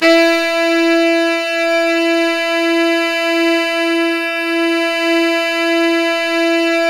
SAX_sfe4x    239.wav